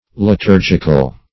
Liturgic \Li*tur"gic\, Liturgical \Li*tur"gic*al\, [Gr.